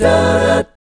1-F#MI7   -R.wav